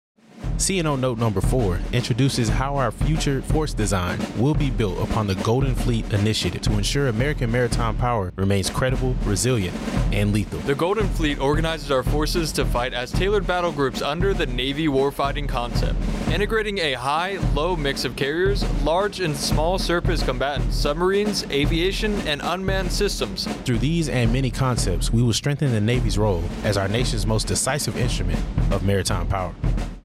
AFN Naples Radio Spot - CNO Note #4